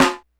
snare03.wav